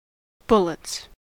Ääntäminen
Ääntäminen US : IPA : [ˈbʊl.ɪts] Haettu sana löytyi näillä lähdekielillä: englanti Käännöksiä ei löytynyt valitulle kohdekielelle. Bullets on sanan bullet monikko.